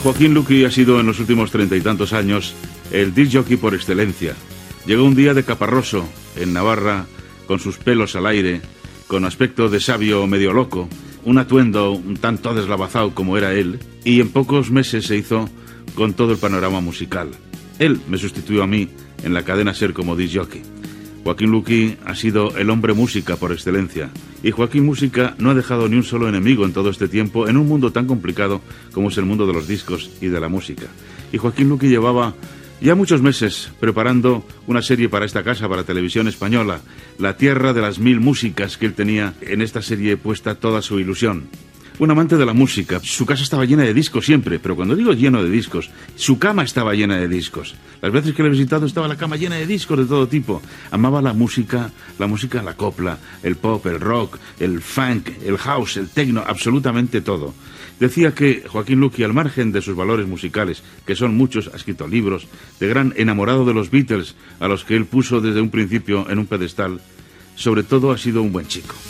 José María Íñigo parla de Joaquín Luqui.
Musical